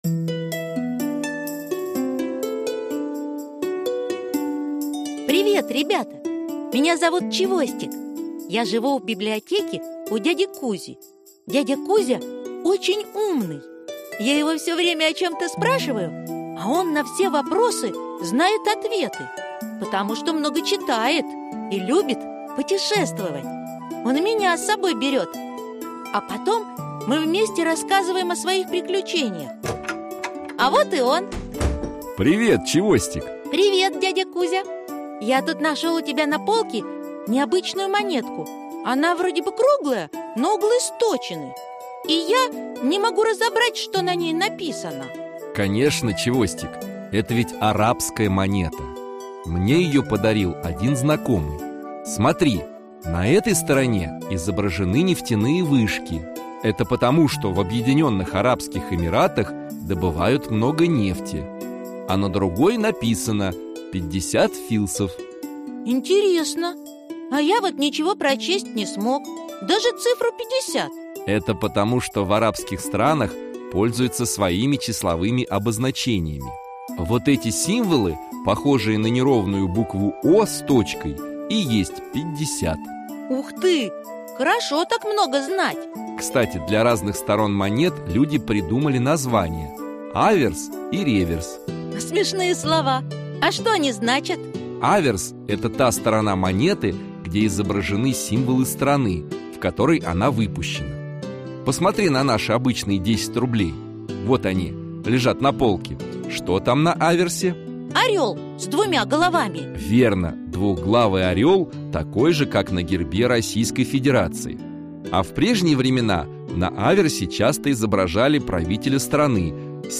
Аудиокнига История денег (часть 1). Монеты | Библиотека аудиокниг